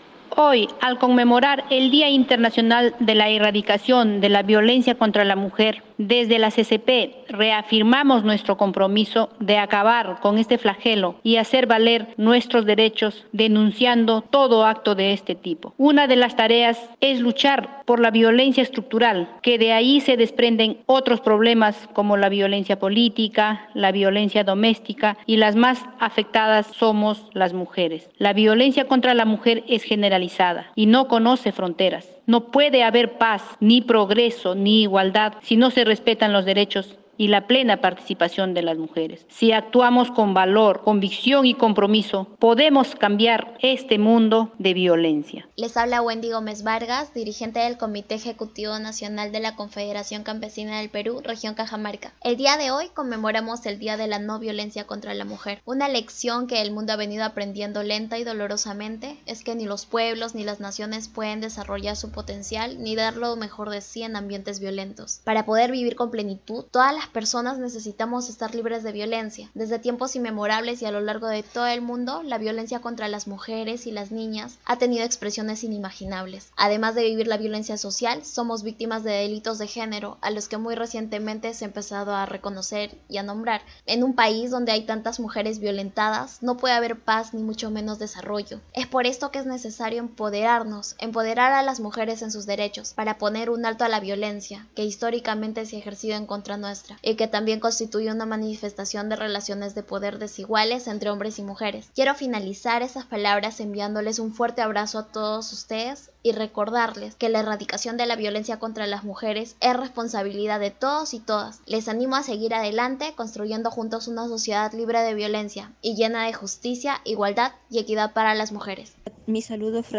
En esta importante fecha, les compartimos los mensajes de dirigentas nacionales y de base de la Confederación Campesina del Perú, CCP, que llaman seguir desarrollando los máximos esfuerzos para erradicar la violencia contra la mujer